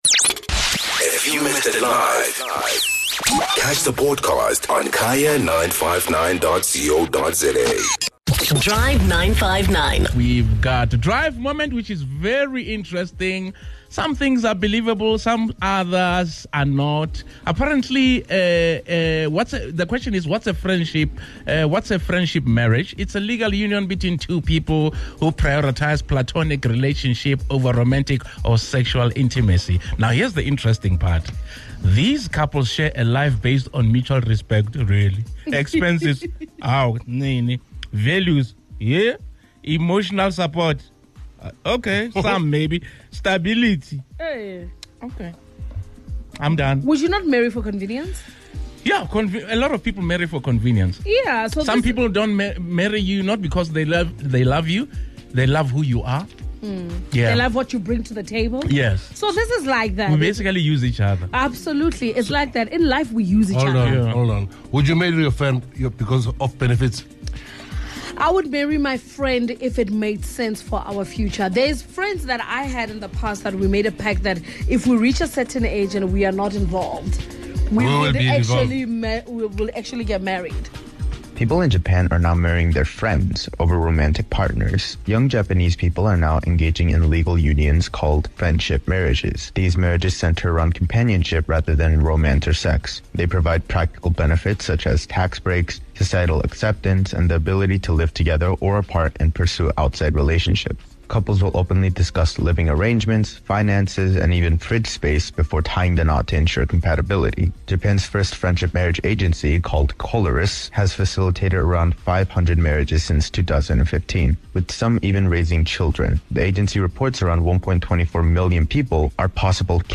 An interesting trend called "Friendship Marriages" in Japan has seen 500 couples getting married to their platonic friends, for the sake of financial stability, having children, and tax benefits. Hear what listeners and the team had to say on this one!